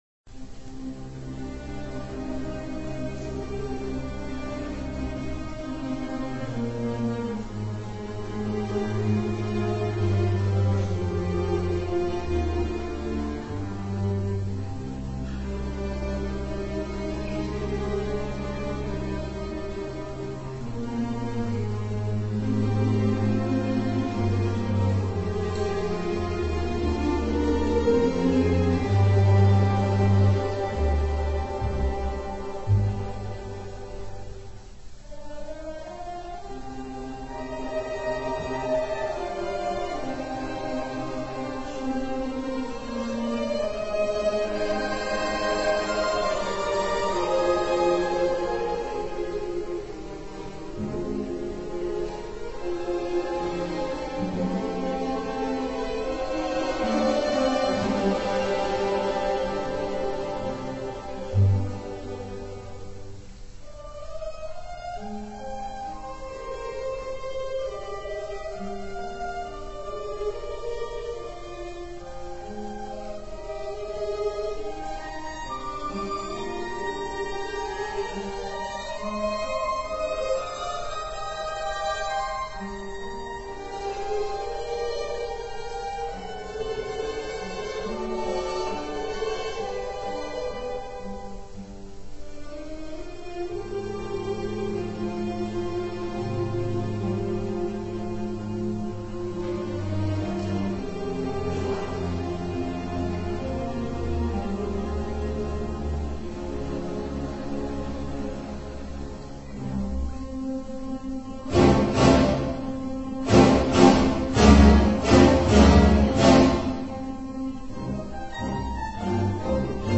オーケストラ  　「落日」には、「物事の勢いが衰えていくさま」という意味がある。
儚さと力強さの共存は、マンドリンの比類なき特徴であると考える次第であるが、マンドリンの祖国イタリアにおけるマンドリンの盛衰にも通じるものがあるのだろうか。